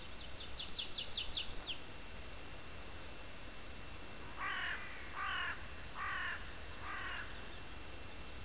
kraehe.wav